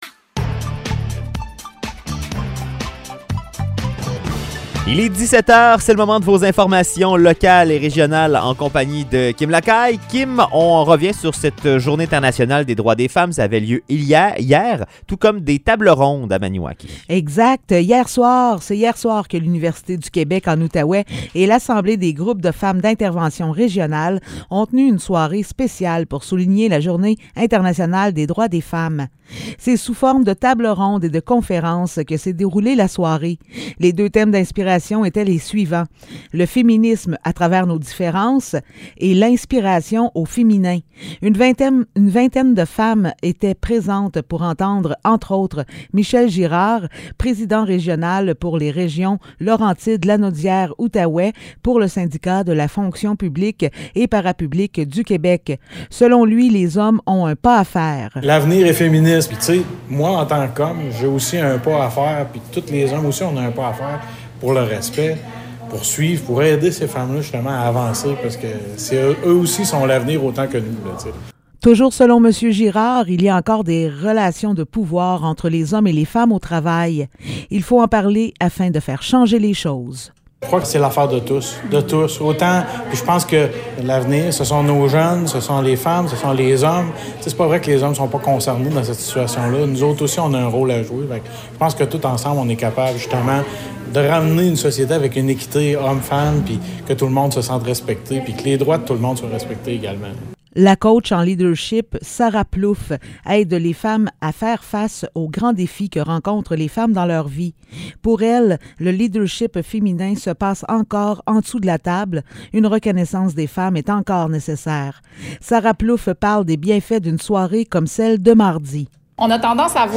Nouvelles locales - 9 mars 2022 - 17 h